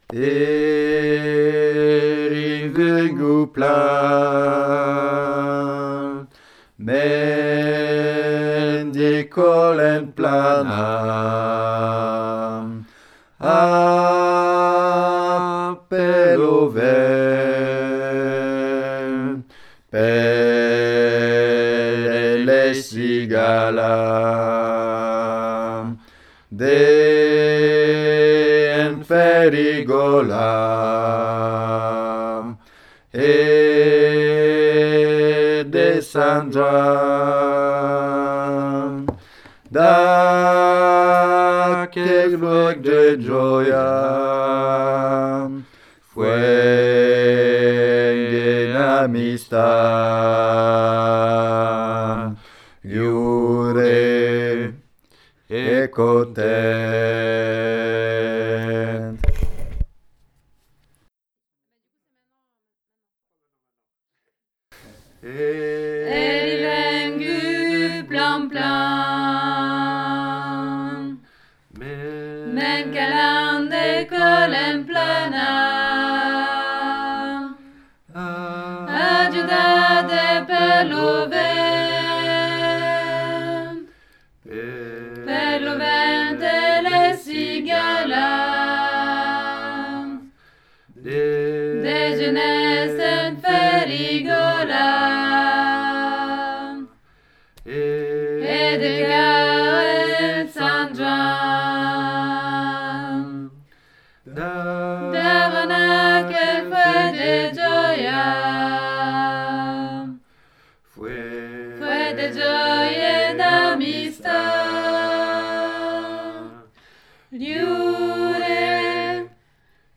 Aquel_cant_Milieu_chaque_voix.mp3